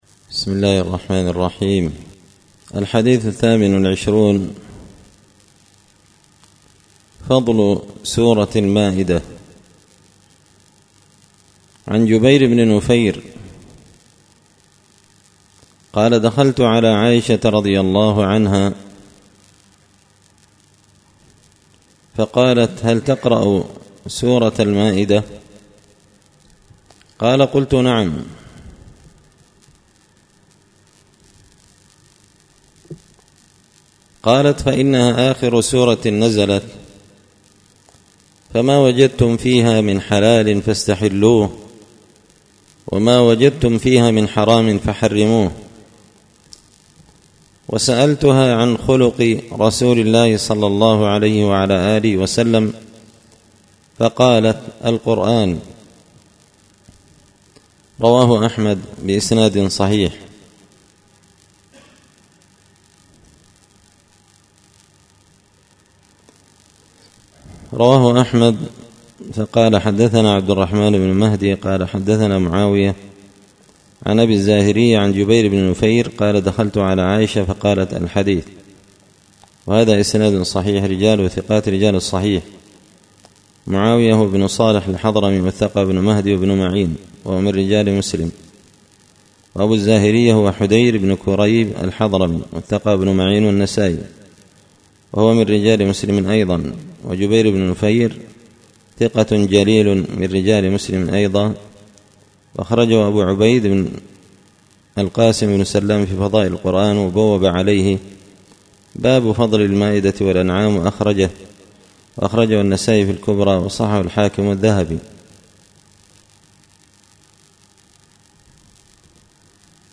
الأحاديث الحسان فيما صح من فضائل سور القرآن ـ الدرس الرابع والعشرون
دار الحديث بمسجد الفرقان ـ قشن ـ المهرة ـ اليمن